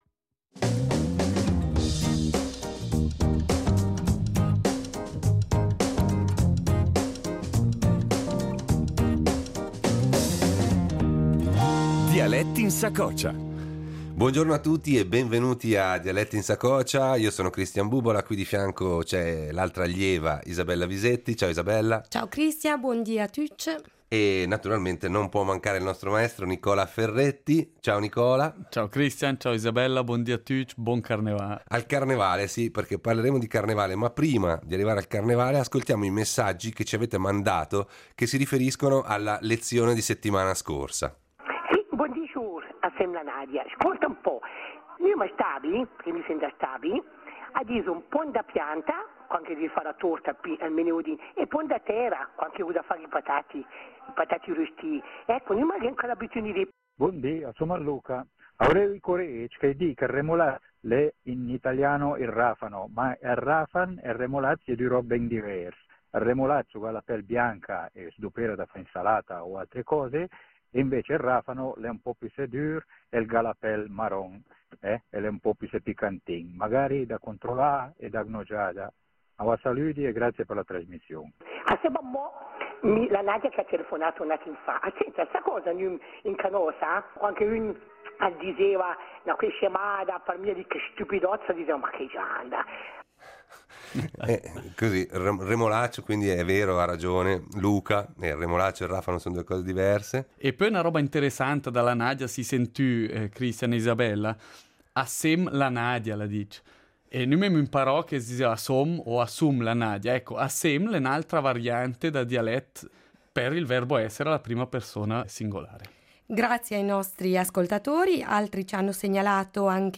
In questa lezione esploriamo